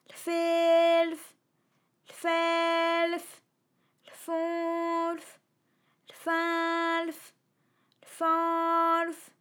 ALYS-DB-001-FRA - First, previously private, UTAU French vocal library of ALYS